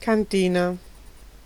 Ääntäminen
IPA: [vut]